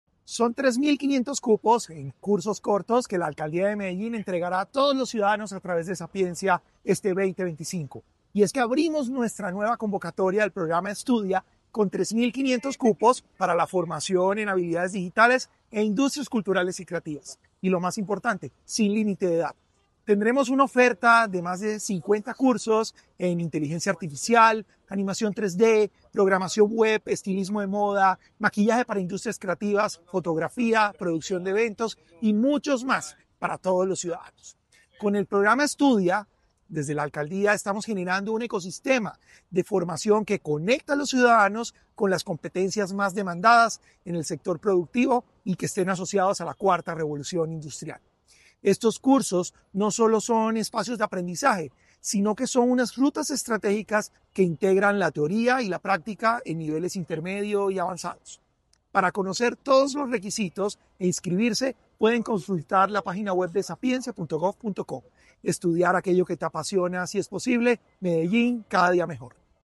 Declaraciones del director general de Sapiencia, Salomón Cruz Zirene
Declaraciones-del-director-general-de-Sapiencia-Salomon-Cruz-Zirene-2.mp3